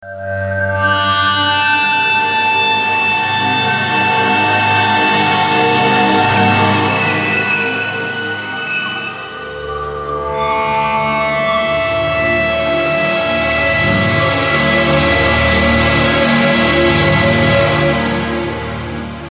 收获反馈 " 延伸的高反馈，突然的结束
描述：很好的均匀，高音调的反馈从小和声中生长出来。最后以我的脚踏板的踢踏声结束。这个样本是用Gibson SG和VOX AC30放大器产生的。它是用两个麦克风（一个Shure SM58和一个AKG）录制的，一个直接放在左边的音箱前面，另一个放在右边的音箱前面。大量的渗漏是不可避免的！
标签： 反馈 吉他
声道立体声